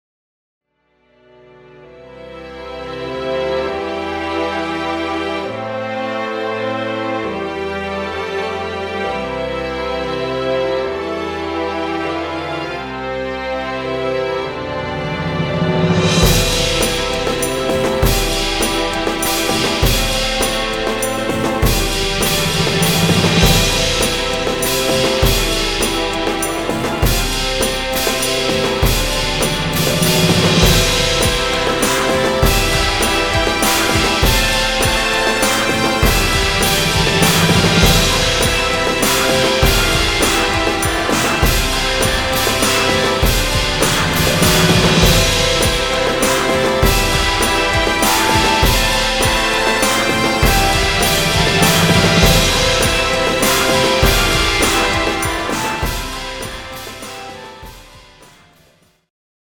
Orchestral arrangement, bright, triumphant, upbeat feel.